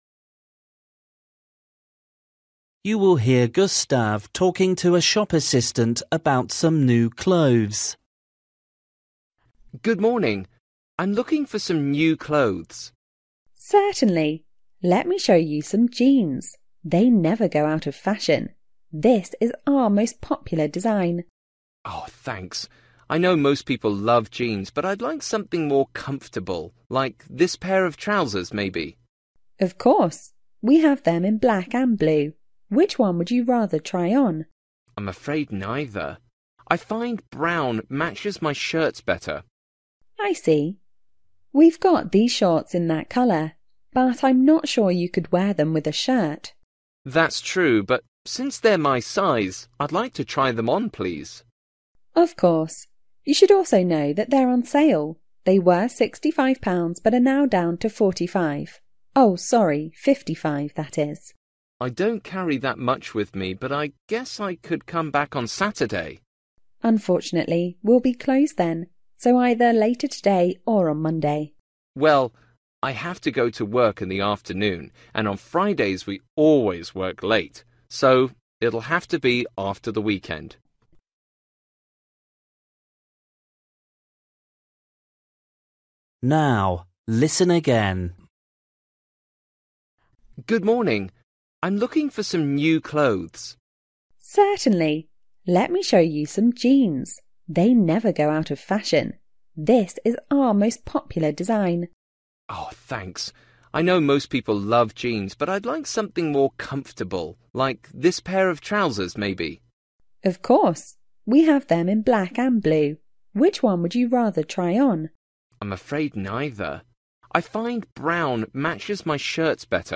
Bài tập trắc nghiệm luyện nghe tiếng Anh trình độ sơ trung cấp – Nghe một cuộc trò chuyện dài phần 28